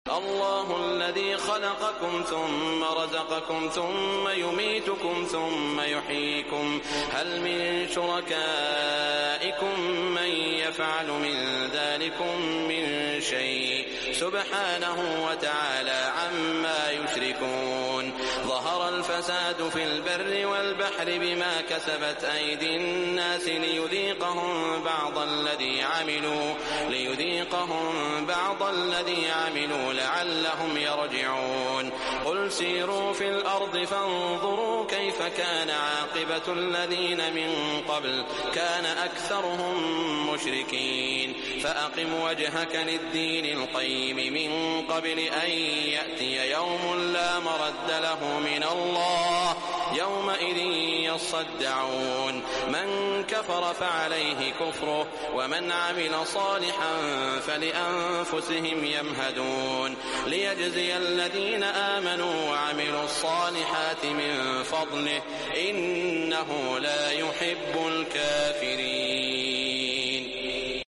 Recitation Of Quran 📖 Sound Effects Free Download